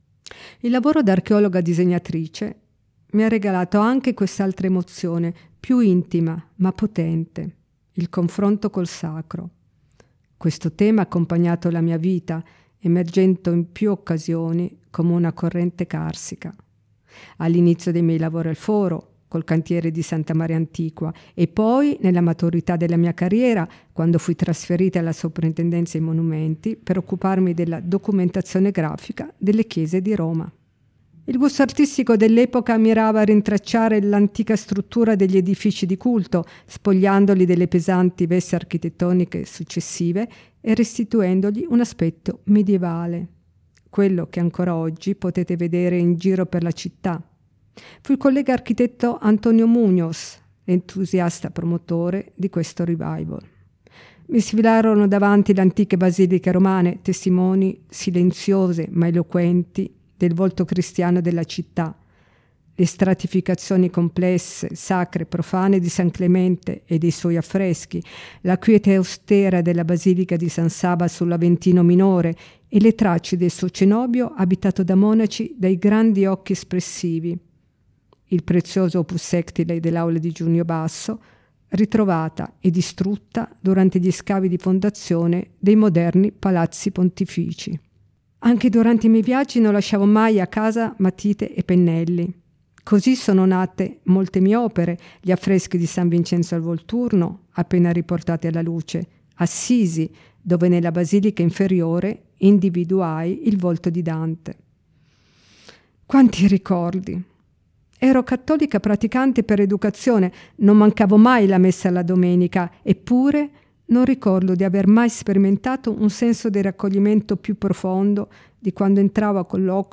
• approfondimenti audio, con il racconto in prima persona di Maria Barosso e le audiodescrizioni delle opere selezionate
Storytelling Maria Barosso: